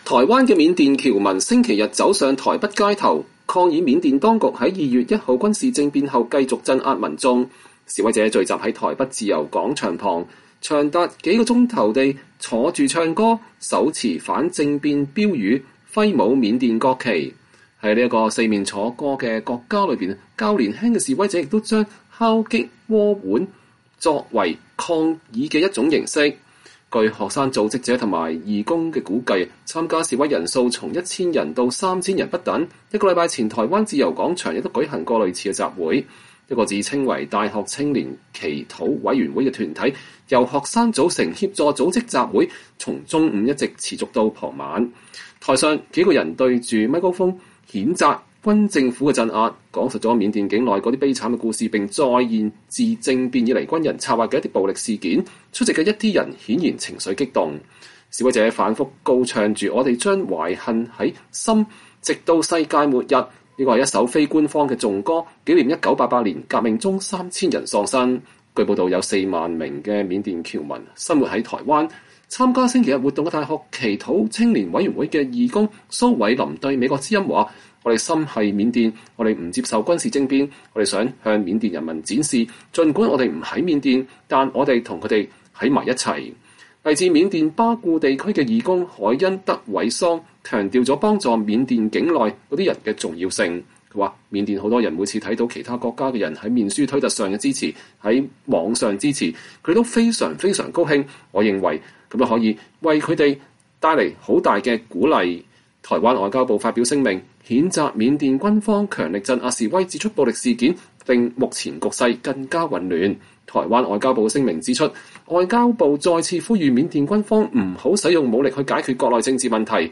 示威者聚集在台北自由廣場旁，長達數小時地坐著唱歌，手持反政變標語，揮舞緬甸國旗。在這個四面楚歌的國家裡，較年輕的示威者也把敲擊鍋碗瓢盆作為抗議的一種形式。
台上，幾個人對著麥克風譴責軍政府的鎮壓，講述了緬甸境內那些悲慘故事，並再現了自政變以來軍人策劃的一些暴力事件。出席的一些人顯然情緒激動。
示威者反复唱著“我們將懷恨在心直到世界末日”，這是一首非官方的頌歌，以紀念1988年革命中3000人喪生。